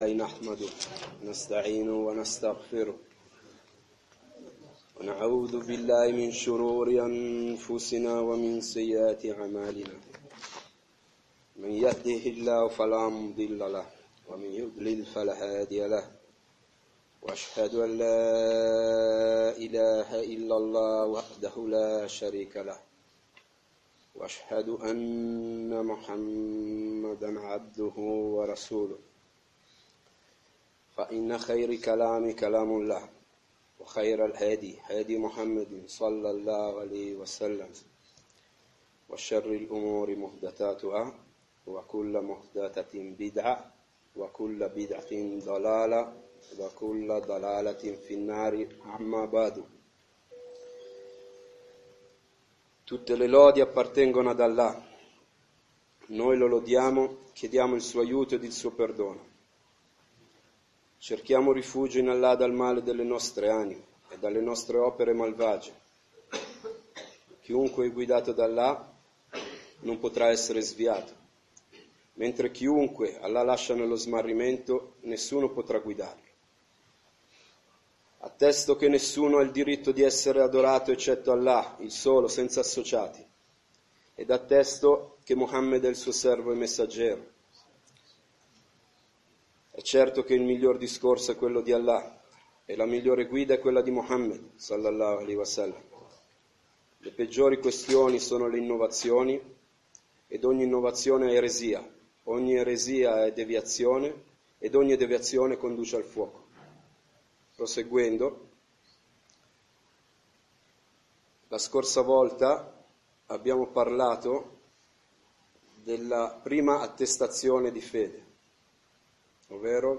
Lezioni